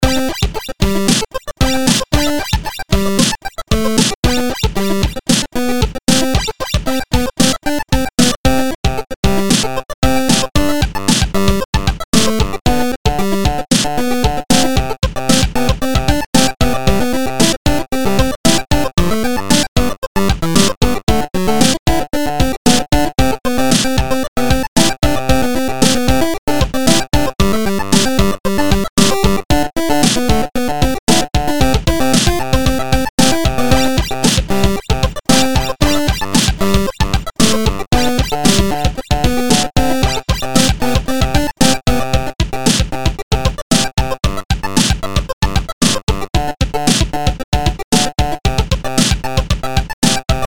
This was a chiptune style background music loop I created a while ago when I used FL Studio. I resurrected it for my submission to the Summer Game Jam 2025 and decided to use it again.
chiptune punchy cute
loopable